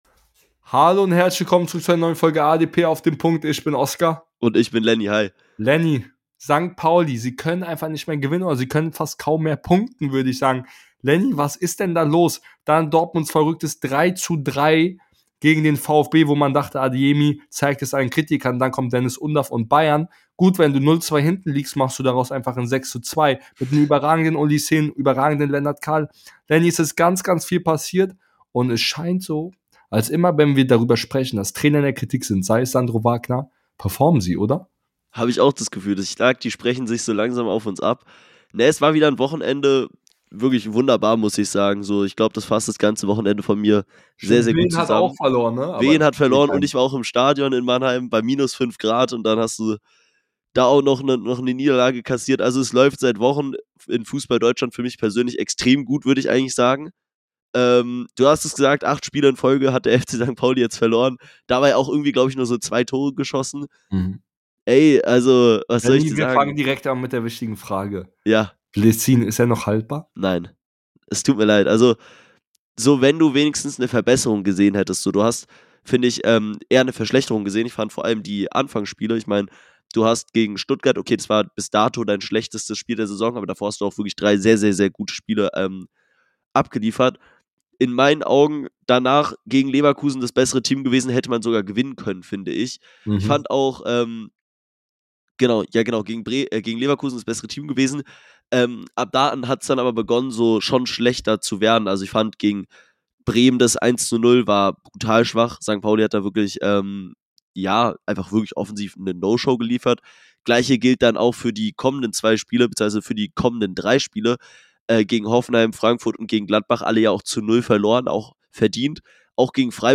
In der heutigen Folge sprechen die beiden Hosts über St.Paulis Krise ,